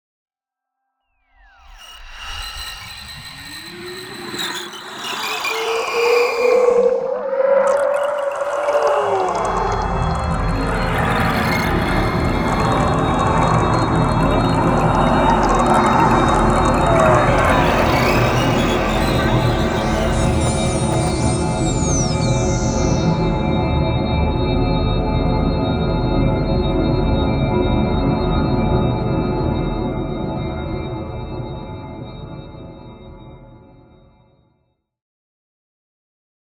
Kyma Wash Just a simple little soundscape created in Kyma and transferred into Pro Tools.